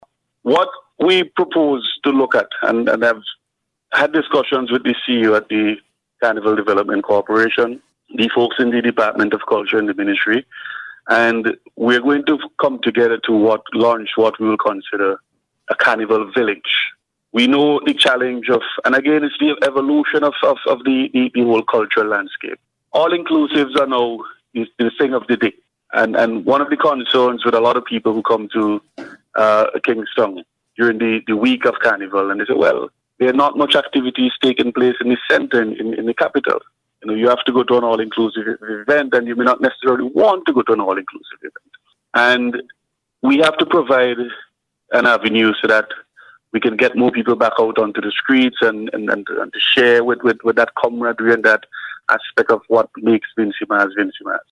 Word of this came from Tourism and Culture Minister Carlos James, as he discussed Carnival-related issues on radio on Sunday.